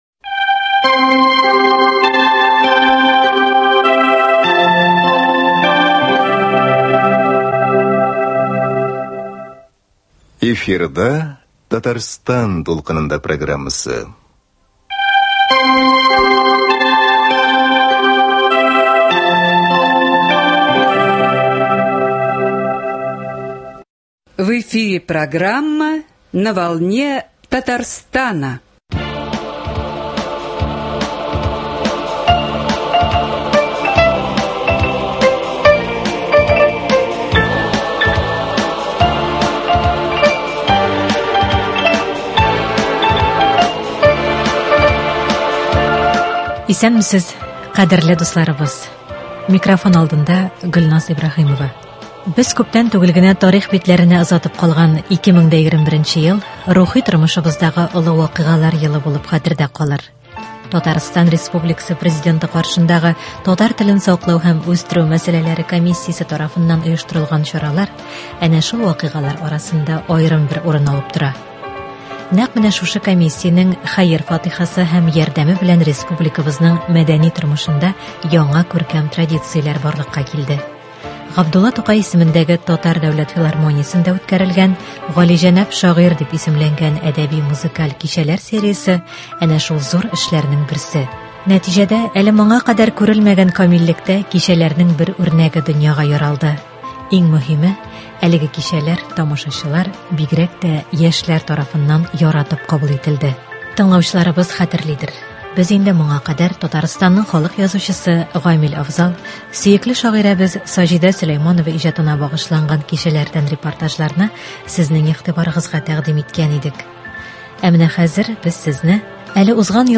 Илдар Юзеев иҗатына багышланган кичәдән репортаж.